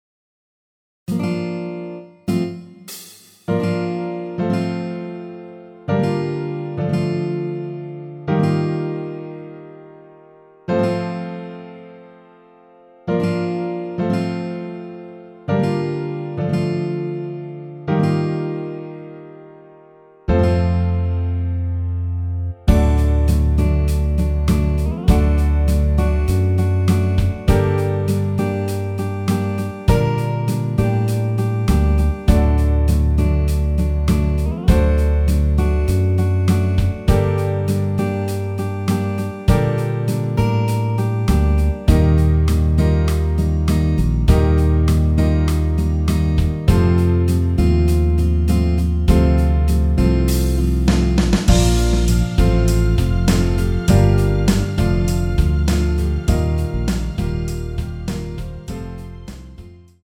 원키에서(-5)내린 MR입니다.
전주 없이 시작 하는 곡이라 1마디 드럼(하이햇) 소리 끝나고 시작 하시면 됩니다.
Bb
앞부분30초, 뒷부분30초씩 편집해서 올려 드리고 있습니다.